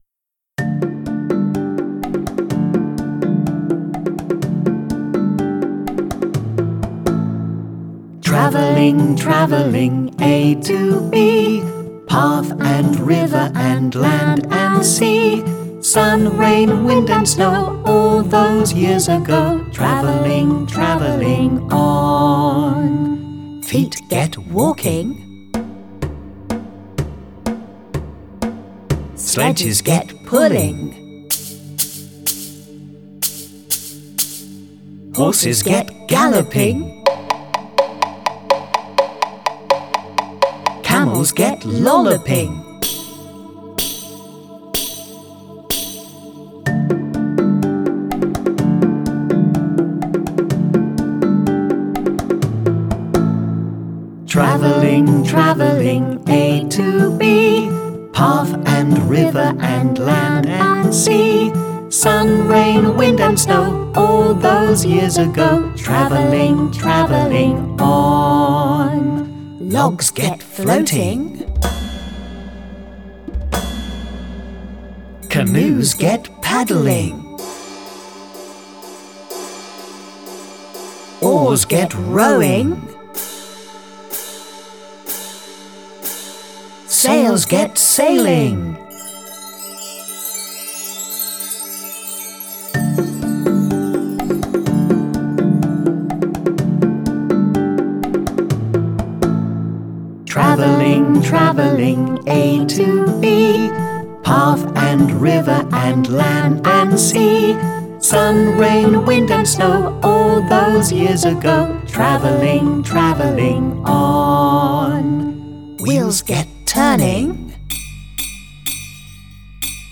Guide vocal